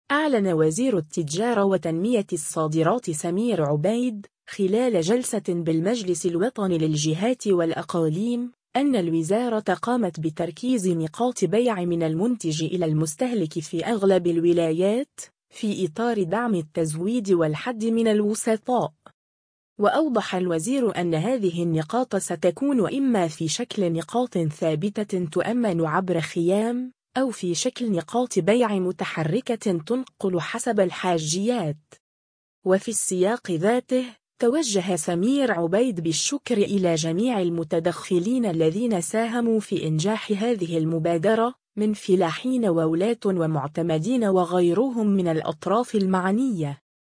أعلن وزير التجارة وتنمية الصادرات سمير عبيد، خلال جلسة بالمجلس الوطني للجهات والأقاليم، أنّ الوزارة قامت بـتركيز نقاط بيع من المنتج إلى المستهلك في أغلب الولايات، في إطار دعم التزويد والحدّ من الوسطاء.